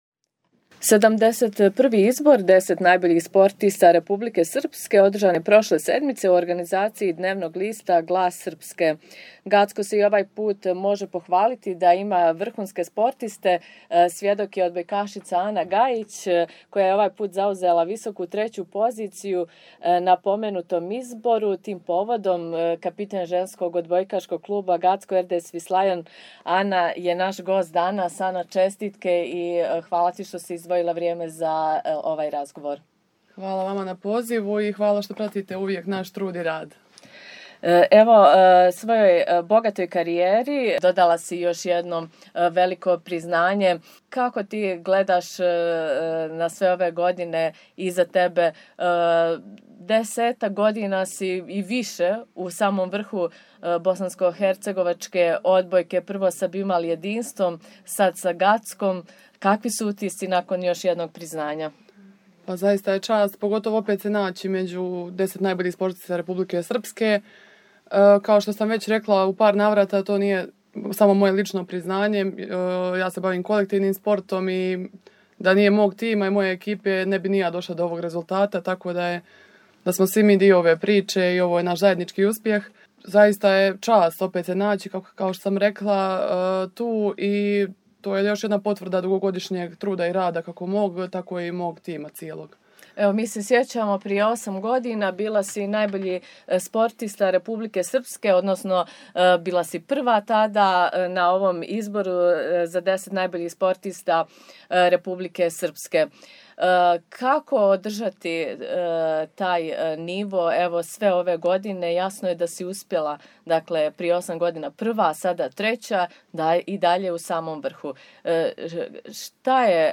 Kako vidi dosadašnju karijeru i šta očekuje u budućnosti od svog tima saznaćete u razgovoru koji slijedi… https